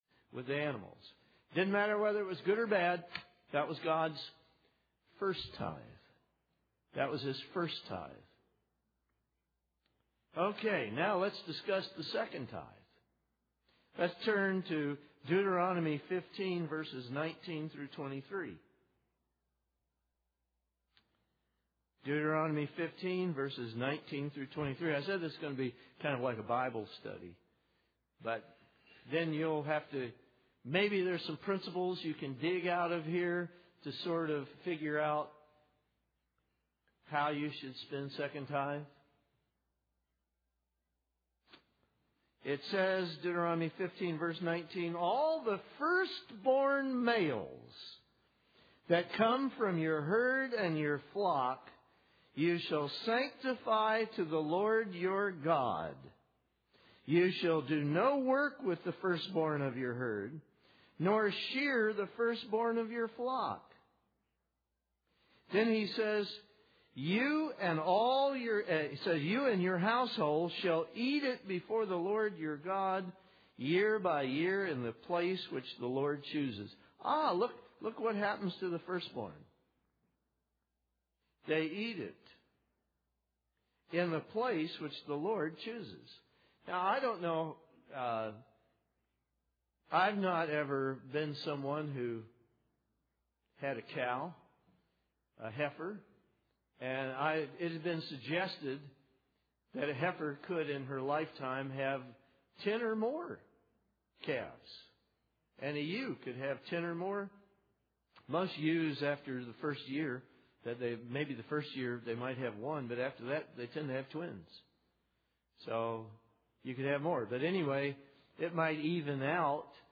This sermon covers principles from the Bible on how members should spend the second tithe they have saved in order to attend the Feast of Tabernacles each fall. (Please note, the first part of the sermon is missing.)
Given in Nashville, TN
UCG Sermon Studying the bible?